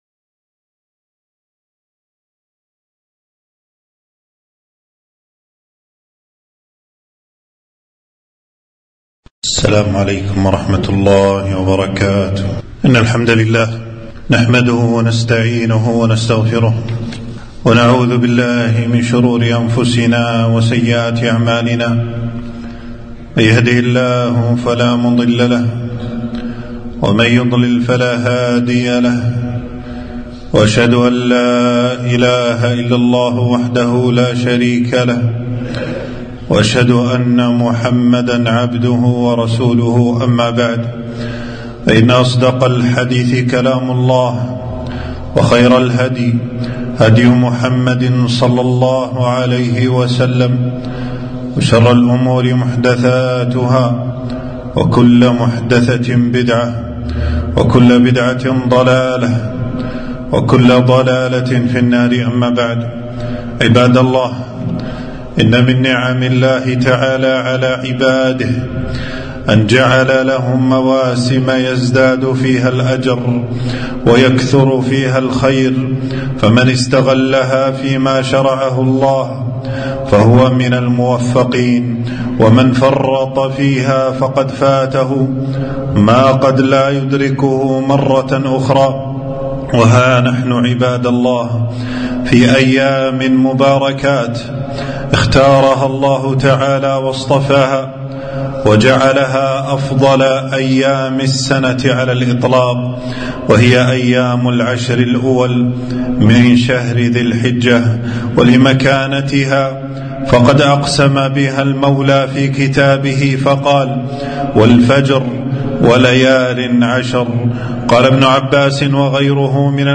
خطبة - العشر الأول من ذي الحجة غنيمة العابدين